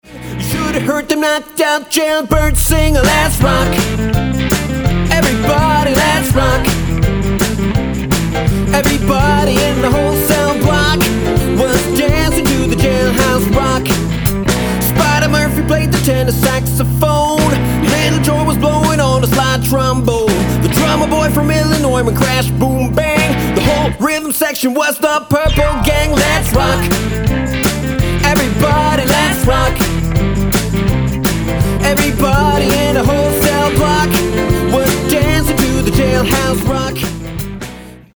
Gesang
Gesang & Schlagzeug
Gitarre
Keyboards
E-Bass